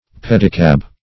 pedicab \ped"i*cab\ (p[e^]d"[i^]*k[a^]b) n.